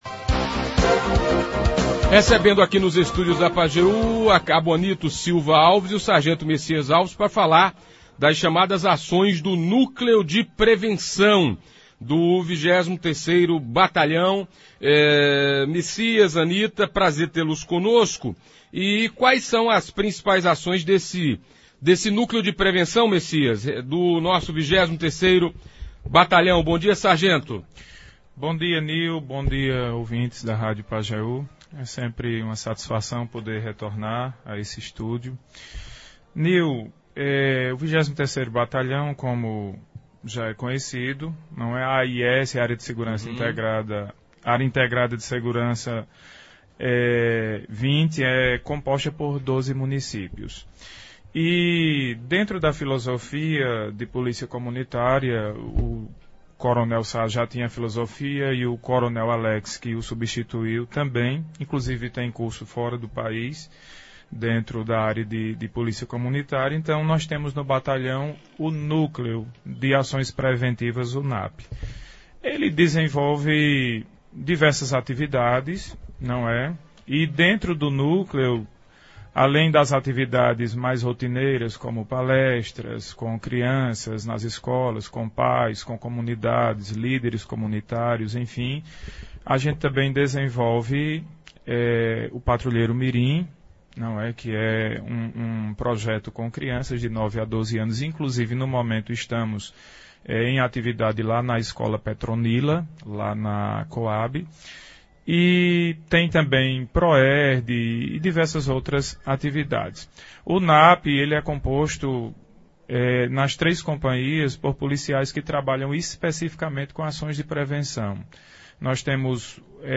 Polícia Militar fala sobre as ações do NAP em Afogados da Ingazeira e região – Rádio Pajeú